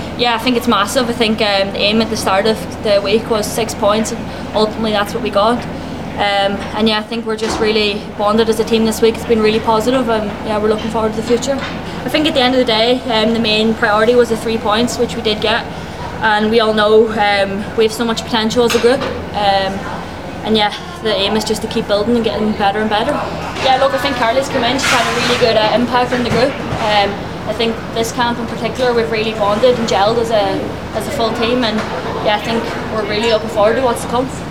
Speaking with the media after the game, Tyler Toland said the group has lots of potential: